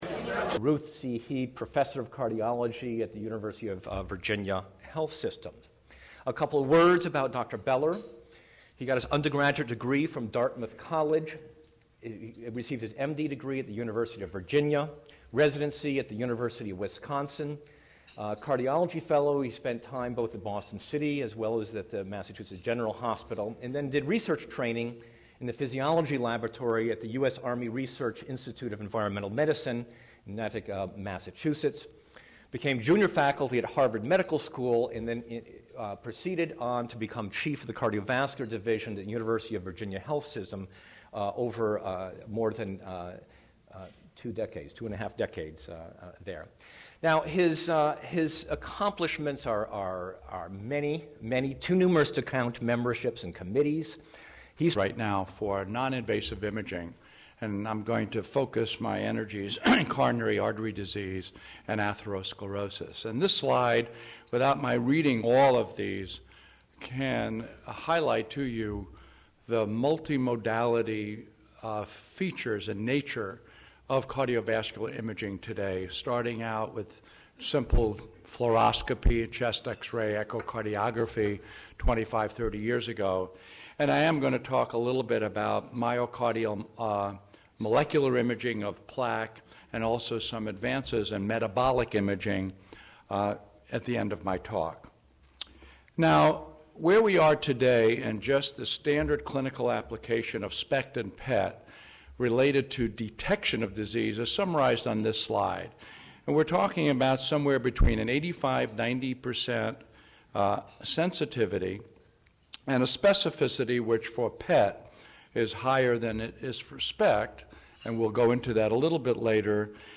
April 22, 2009 | The Ritz-Carlton, Cleveland